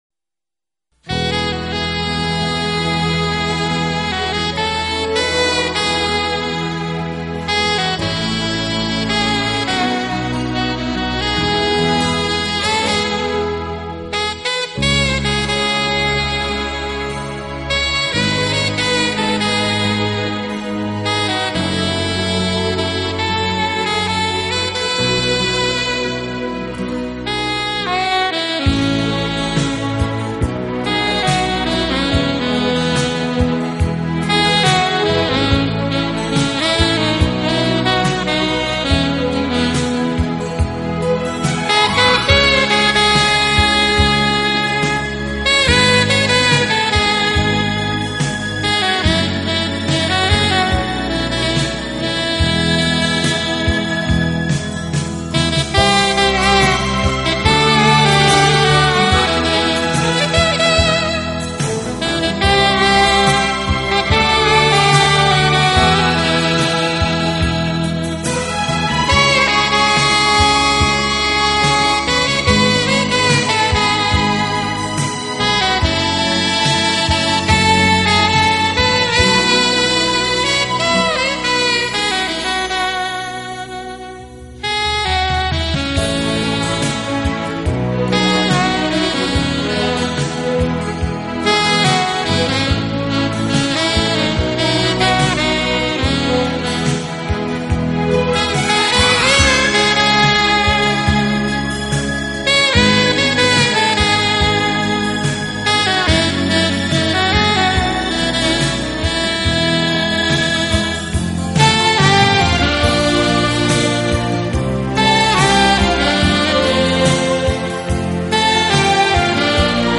一杯香浓咖啡，佐以极似人 声的萨克斯音乐，从容悠然的宽松音色令人倍感休闲的意趣与爽快。